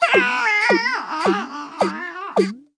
Player Help Sound Effect